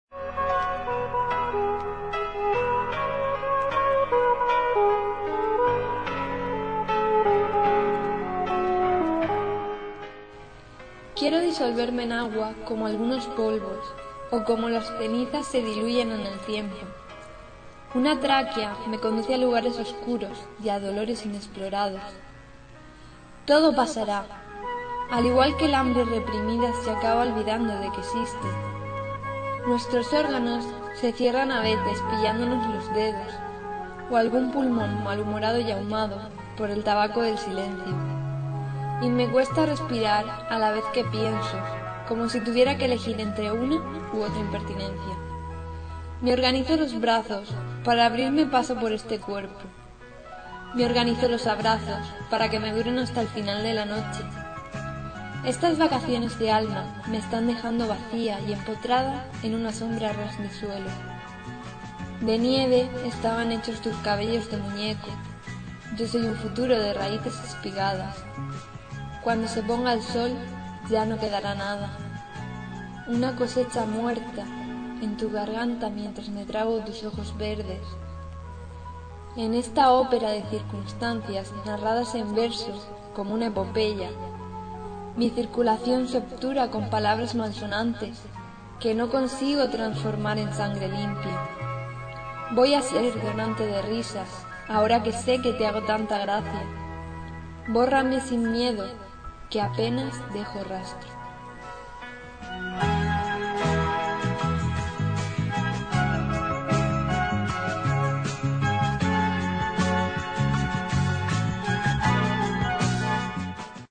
Inicio Multimedia Audiopoemas Disoluciones y otros disolventes.